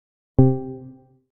6. Отключение режима прозрачности и шумоподавления
airpods-rejym-prozrachnosti-otkl.mp3